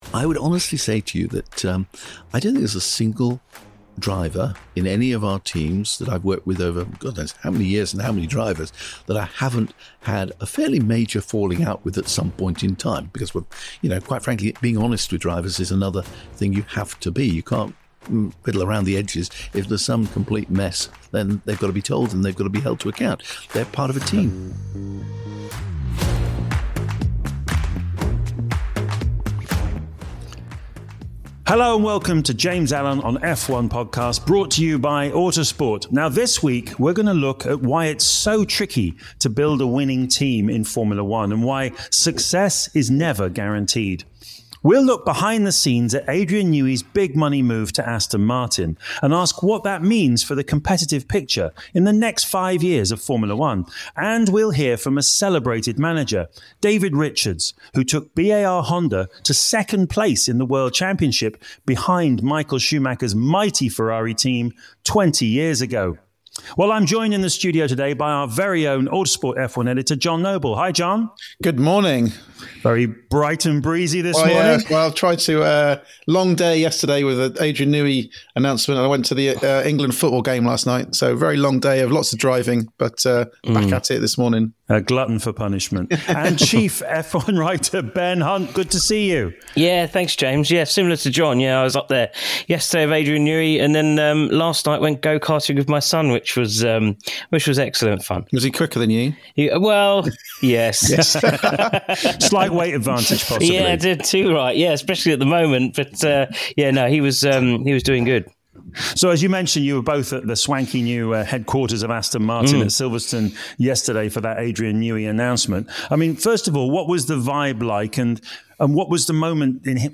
And we hear from celebrated manager David Richards, who took BAR Honda to second place in the World Championship 20 years ago against Michael Schumacher’s dominant Ferrari team.